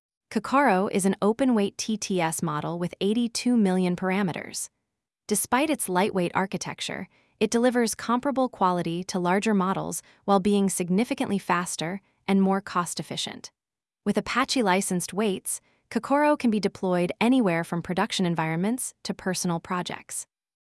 text-to-speech
The output of this action will be a URL link to an audio file where the synthesized speech is stored.